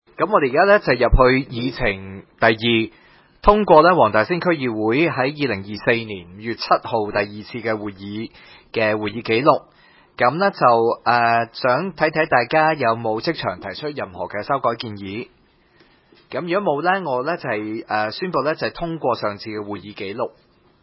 區議會大會的錄音記錄
黃大仙區議會會議室